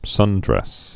(sŭndrĕs)